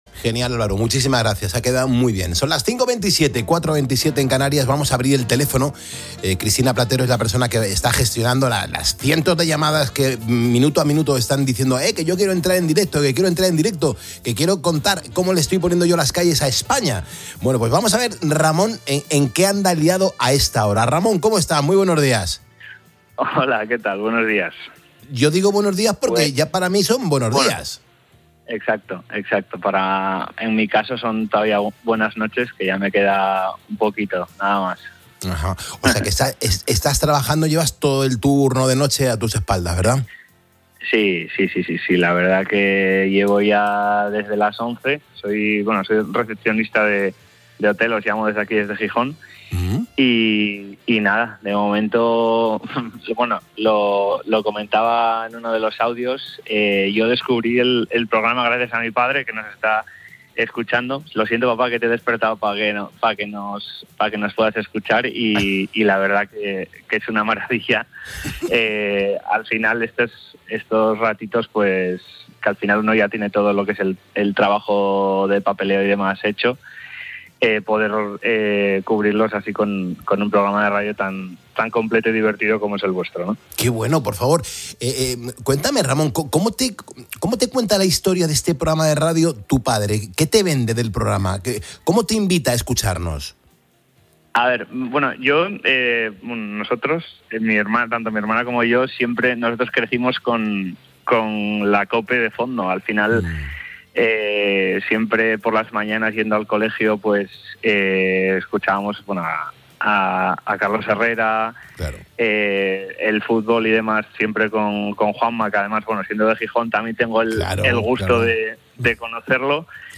Una invitación en directo